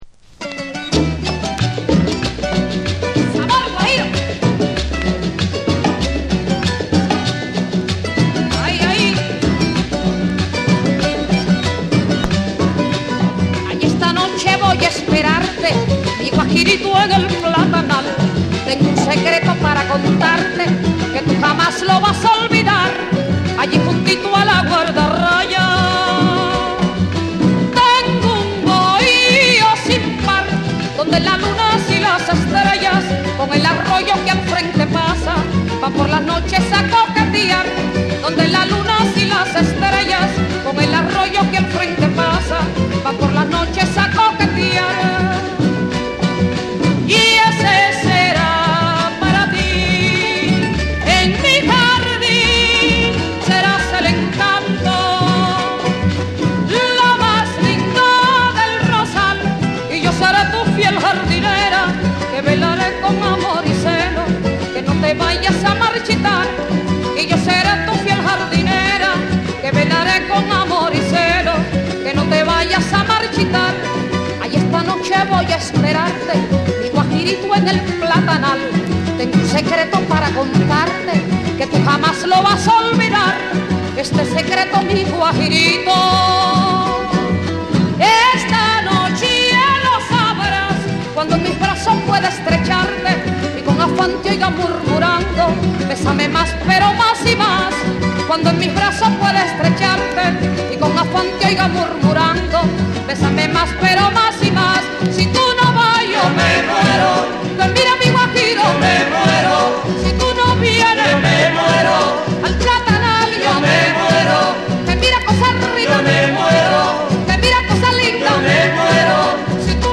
キューバ出身の夫婦デュオ
伝統的なソンやグアラーチャ、グアヒーラを中心に、温かみのあるギターとリズミカルなパーカッション
WORLD